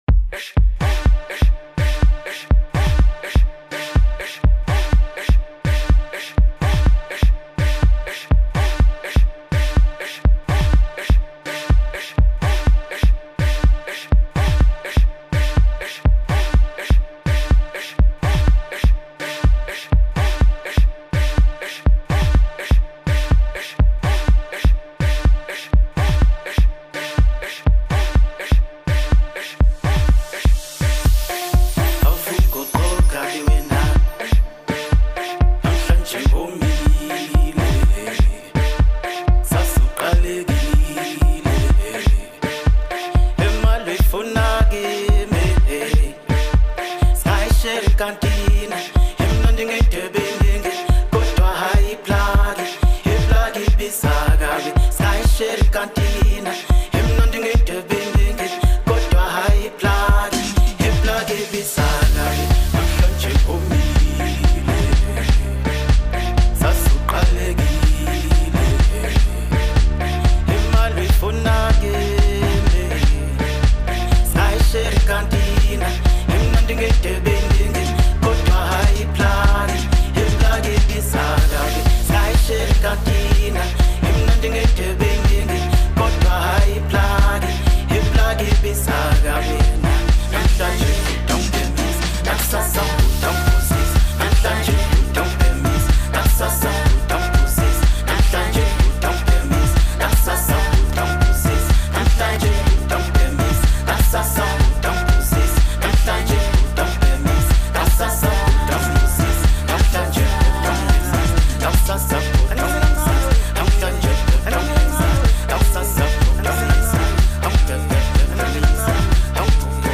you simply don’t want to miss this Gqom hit.